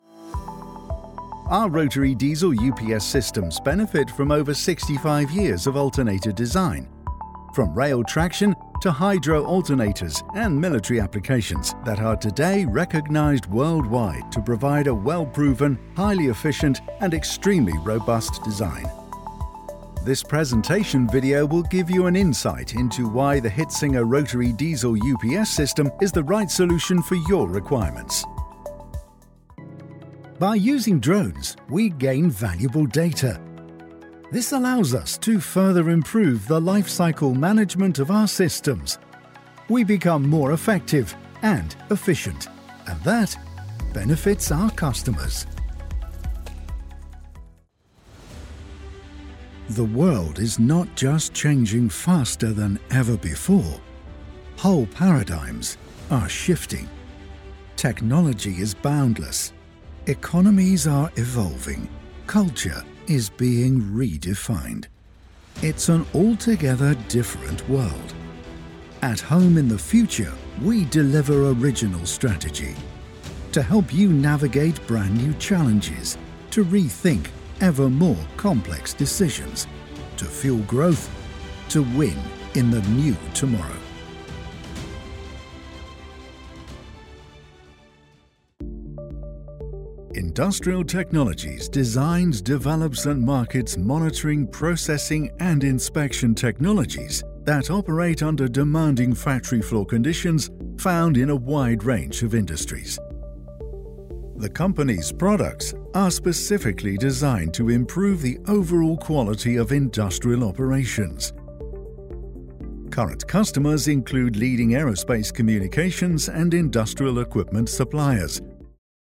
English (British)
Versatile, Reliable, Corporate, Mature, Natural
Corporate